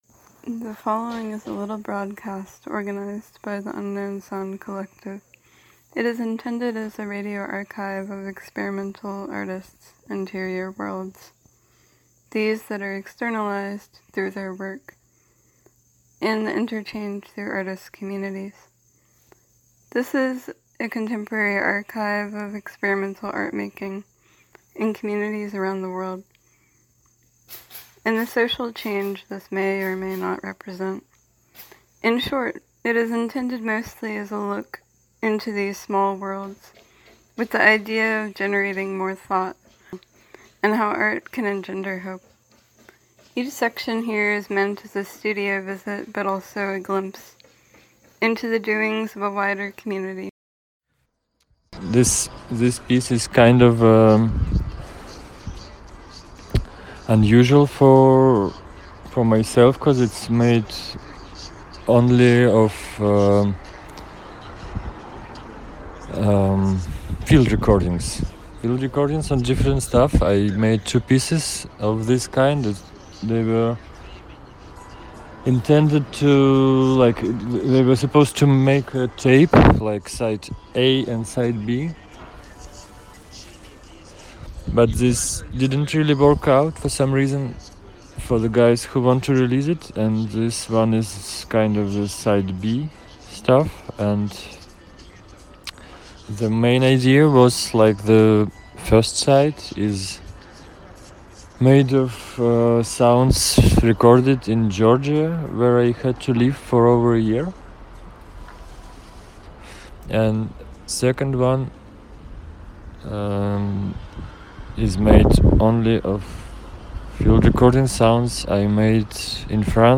"unknown sounds" is intended as a radio archive of experimental artists’ interior worlds, these that are externalized through their work, and the interchange through artist communities.
Each section here is meant as a studio visit, but also a glimpse into the doings of a wider community and the cultural, political repercussions of experimental sound and art-making. guest this week: nazlo records is a currently nomadic experimental label originally coming from Russia, exploring all types of weird sound and media formats like tapes, lathe cut records, reel-to-reel tapes, floppy discs etc.